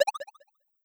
Success2.wav